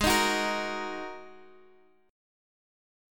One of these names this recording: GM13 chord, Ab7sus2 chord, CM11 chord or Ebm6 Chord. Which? Ab7sus2 chord